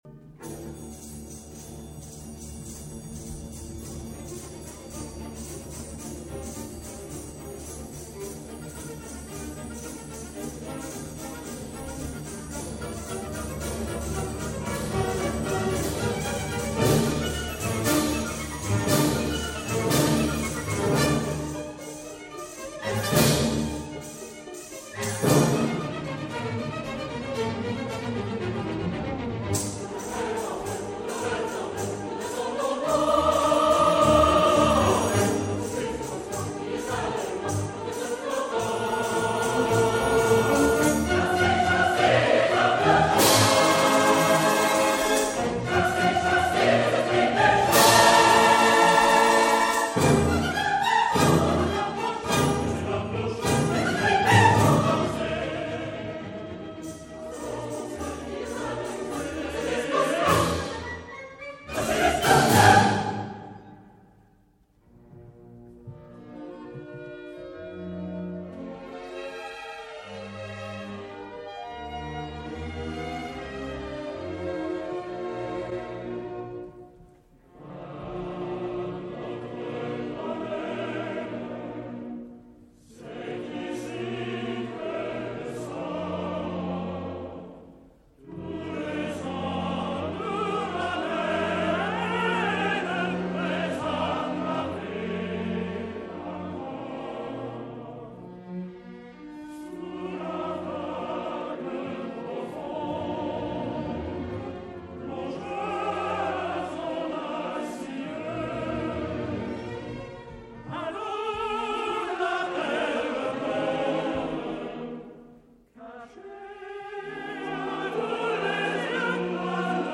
Orchestre : 2 flûtes (1 petite flûte), 2 hautbois (1 cor anglais), 2 clarinettes, 2 bassons, 4 cors, 2 cornets à pistons, 3 trombones, tuba, timbales, cymbales, tambour de basque, triangle, tamtam, tambour, harpes, cordes.